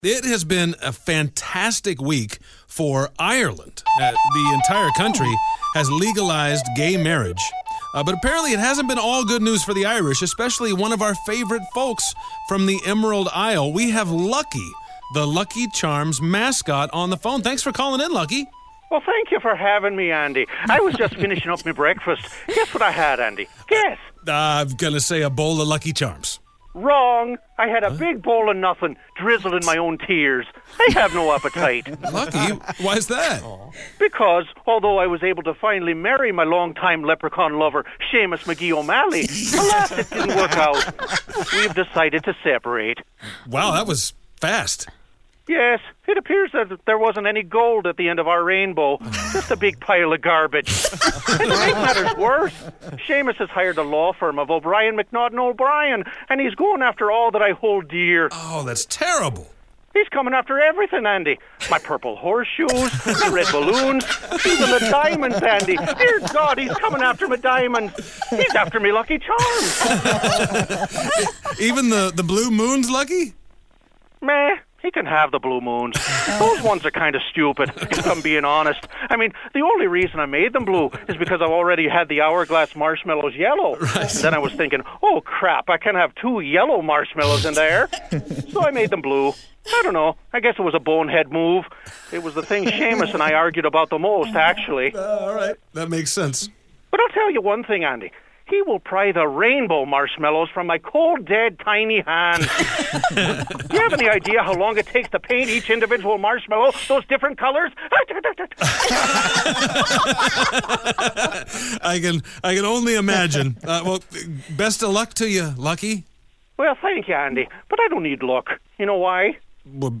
Lucky the Lucky Charms mascot calls the show!